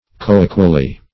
coequally - definition of coequally - synonyms, pronunciation, spelling from Free Dictionary Search Result for " coequally" : The Collaborative International Dictionary of English v.0.48: Coequally \Co*e"qual*ly\, adv.